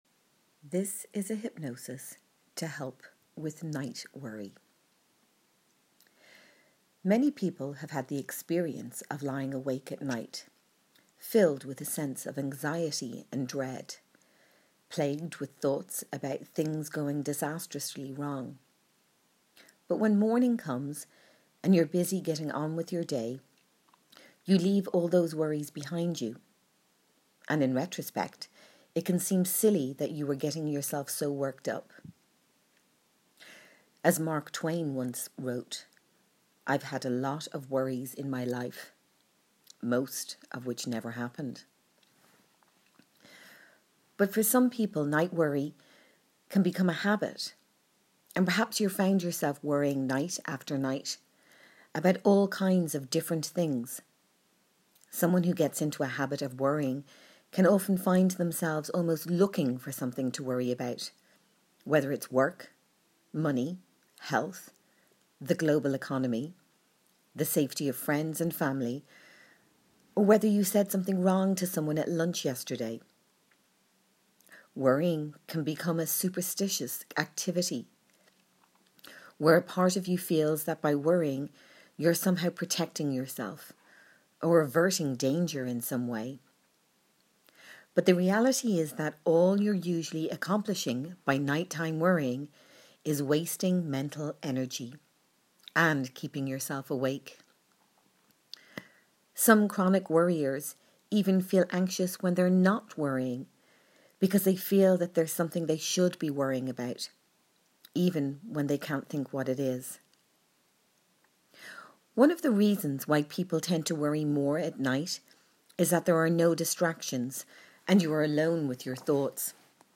WcFrd0xdQRqAFOfuhjCu_Meditation_-_Night_worry_.m4a